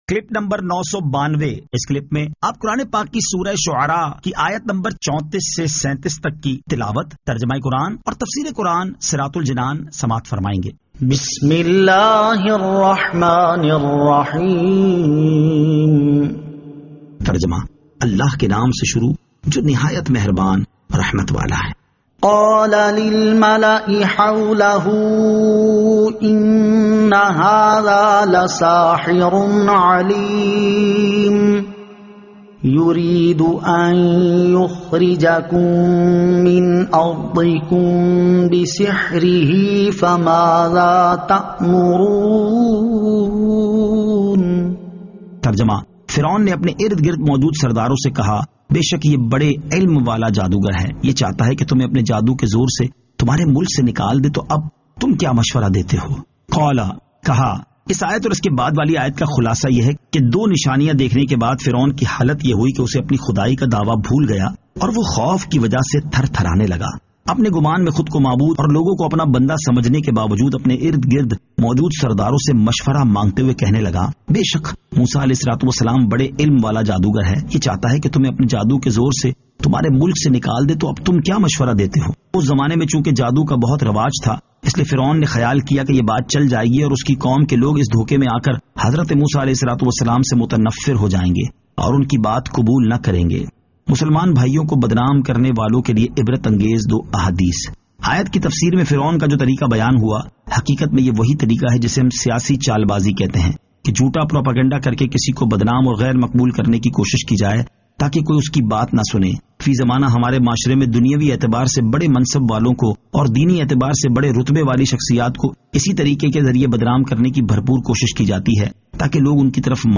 Surah Ash-Shu'ara 34 To 37 Tilawat , Tarjama , Tafseer